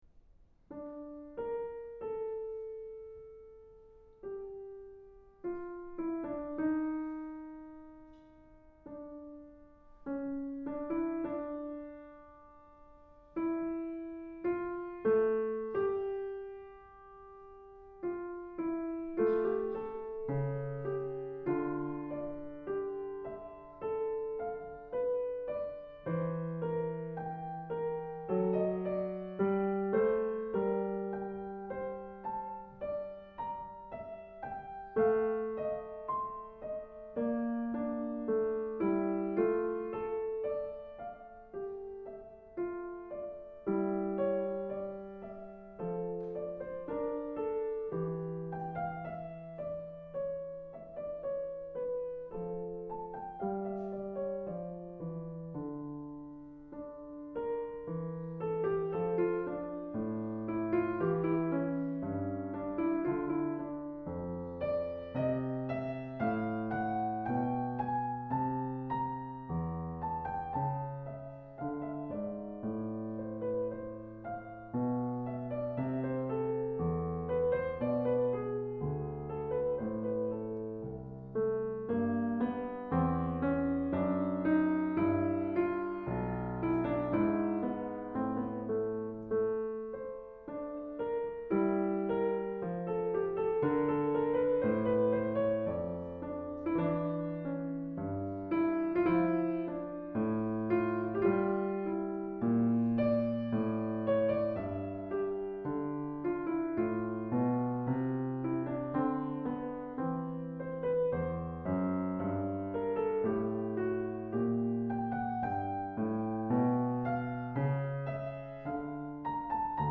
Recital